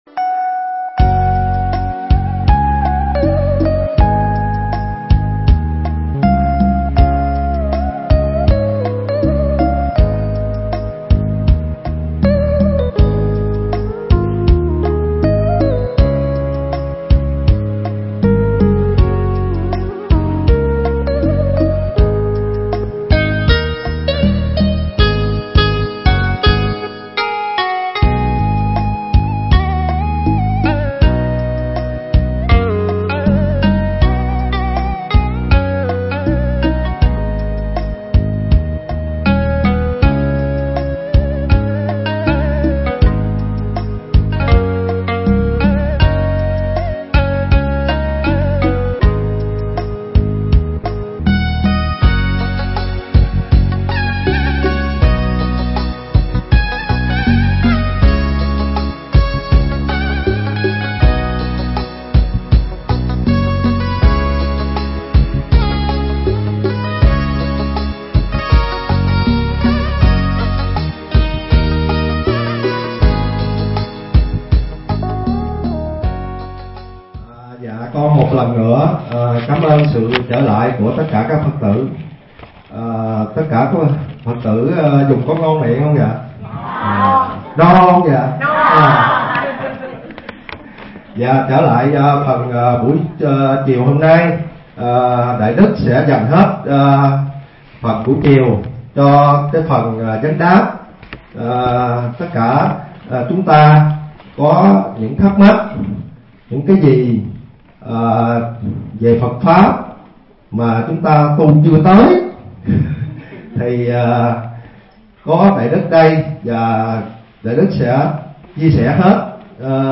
giảng tại Hindu Temple (Bang Pennsylvania, Hoa Kỳ)